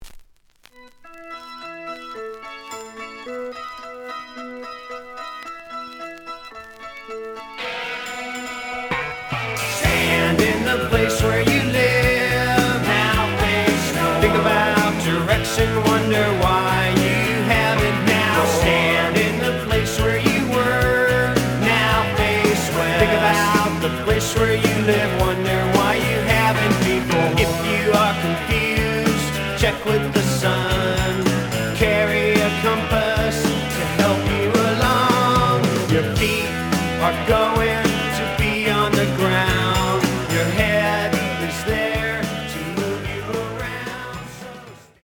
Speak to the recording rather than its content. The audio sample is recorded from the actual item. ●Format: 7 inch Slight edge warp.